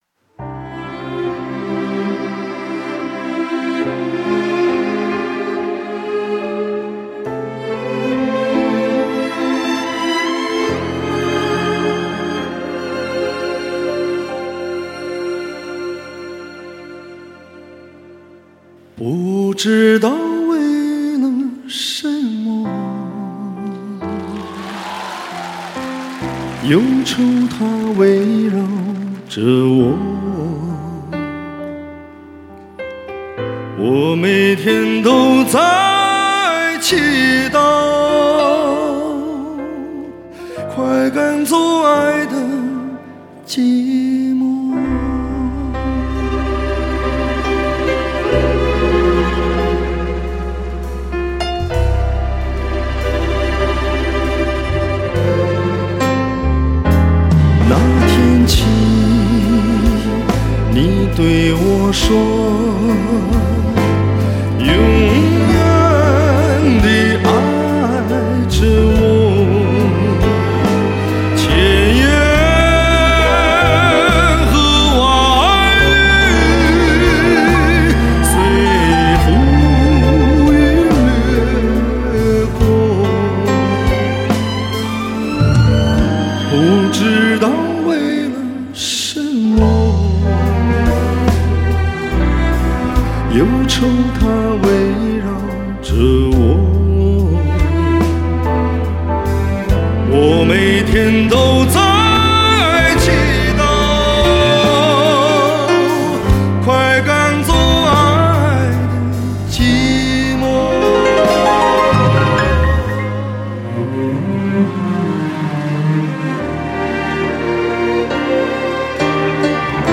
24BIT-96KHZ 纯数码录音制作
中国男歌手